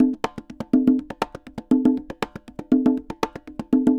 Congas_Salsa 120_2.wav